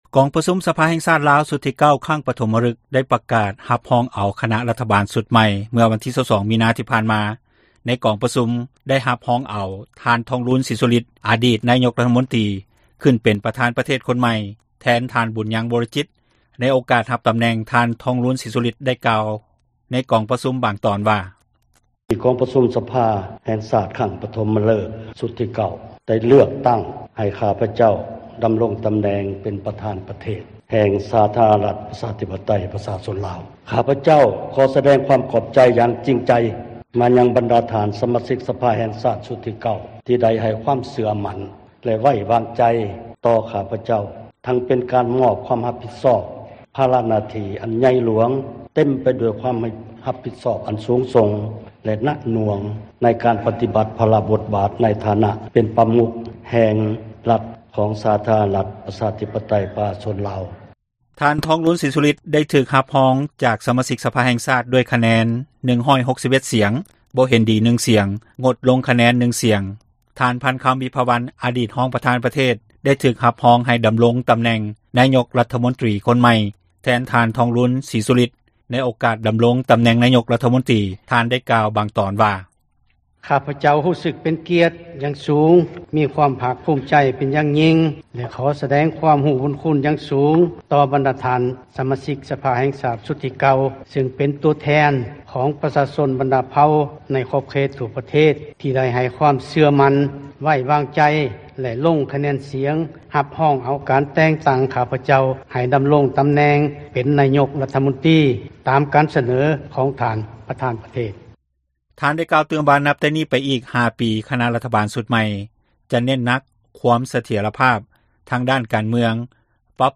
ກອງປະຊຸມ ສະພາແຫ່ງຊາດລາວ ຊຸດທີ 9 ຄັ້ງປະຖົມມະຣຶກ ໄດ້ປະກາດ ຮັບຮອງເອົາຄະນະຣັຖບານ ຊຸດໃໝ່ ເມື່ອວັນທີ 22 ມິນາ ຜ່ານມາ. ໃນກອງປະຊຸມໄດ້ຮັບຮອງເອົາ ທ່ານ ທອງລຸນ ສີສຸລິດ ອາດີດນາຍົກຣັຖມົນຕຼີ ຂື້ນເປັນປະທານປະເທດຄົນໃໝ່ ແທນ ທ່ານ ບຸນຍັງ ວໍລະຈິດ. ໃນໂອກາດຮັບຕຳແໜ່ງ ທ່ານ ທອງລຸນ ສີສຸລິດ ໄດ້ກ່າວ ໃນກອງປະຊຸມ ບາງຕອນວ່າ.
ທ່ານ ພັນຄຳ ວິພາວັນ ອາດີດ ຮອງ ປະທານປະເທດ ໄດ້ຖືກຮັບຮອງໃຫ້ດຳຣົງຕຳແໜ່ງ ນາຍົກ ຣັຖມົນຕຼີຄົນໃໝ່ ແທນ ທ່ານ ທອງລຸນ ສີສຸລິດ. ໃນໂອກາດຮັບຕຳແໜ່ງນາຍົກ ຣັຖມົນຕຼີ ທ່ານ ໄດ້ກ່າວບາງຕອນວ່າ: